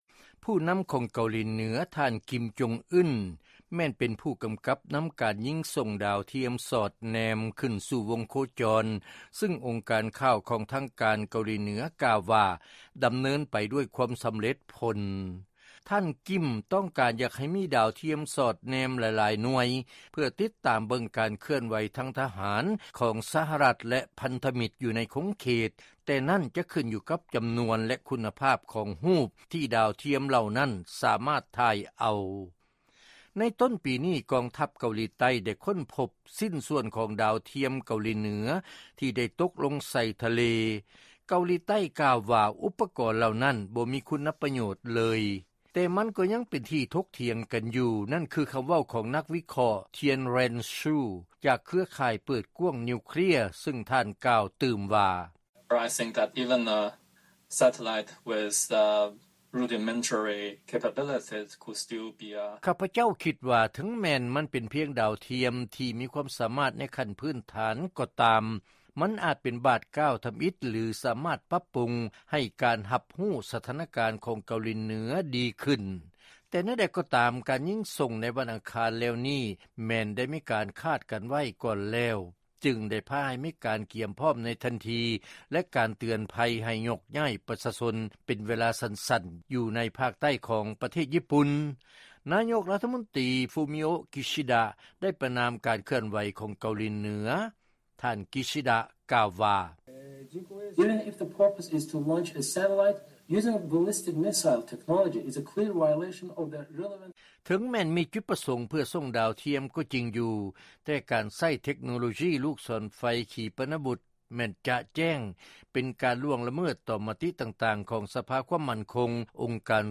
ເຊີນຟັງລາຍງານ ເກົາຫຼີເໜືອກ່າວວ່າ ຕົນໄດ້ສົ່ງດາວທຽມສອດແນມ ຂຶ້ນສູ່ວົງໂຄຈອນ ດ້ວຍຄວາມສຳເລັດ